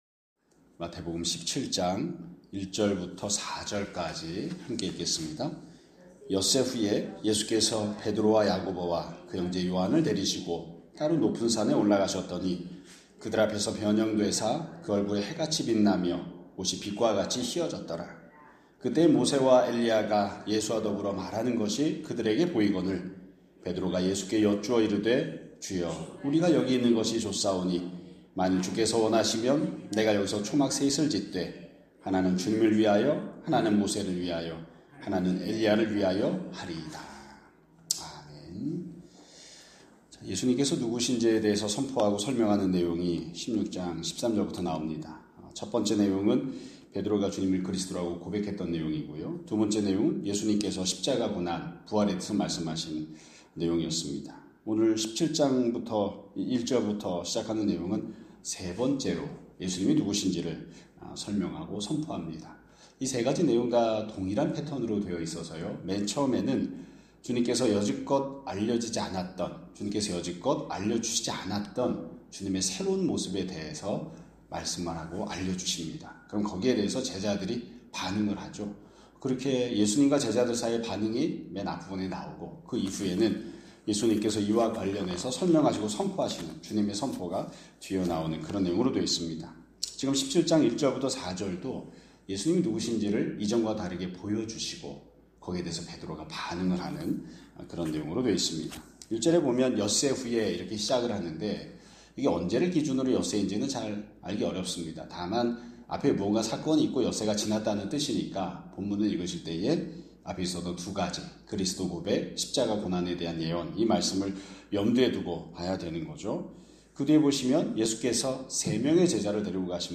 2025년 11월 21일 (금요일) <아침예배> 설교입니다.